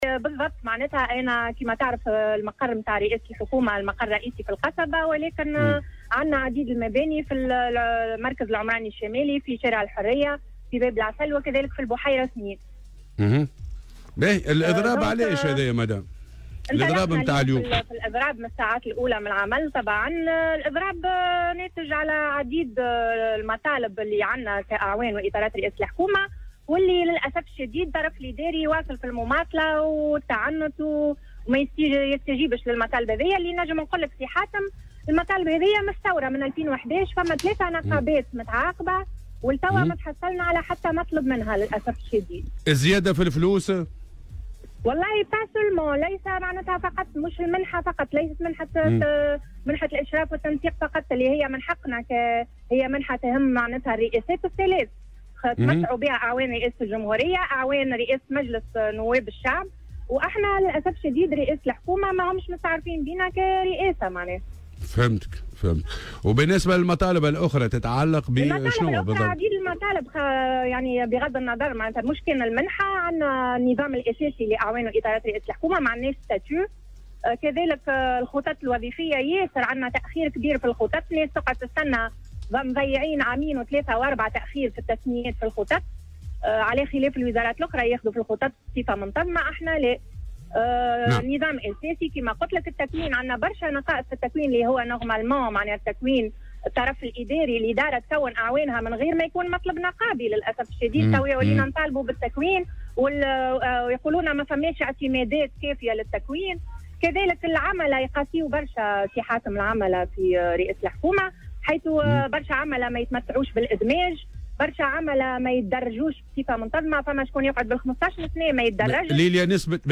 في مداخلة لها اليوم في برنامج "صباح الورد" على "الجوهرة أف أم"